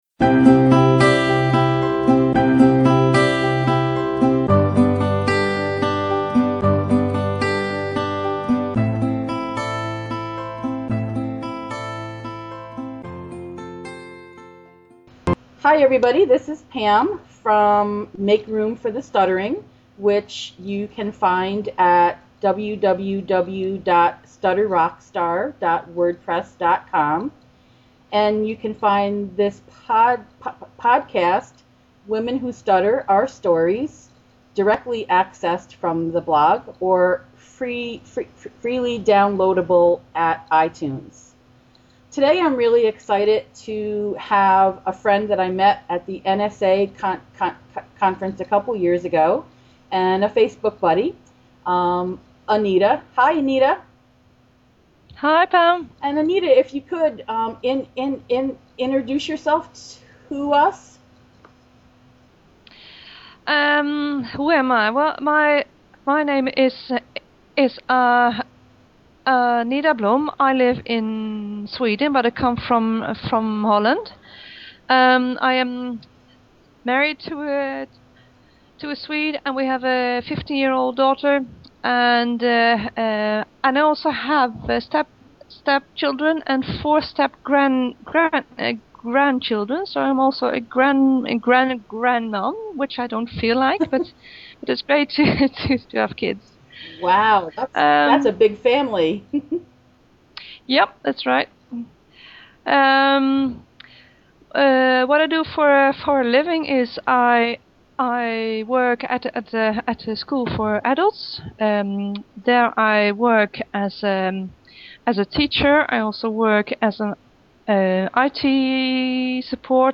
It did feel completely right and comfortable chatting with each other via skype and webcam.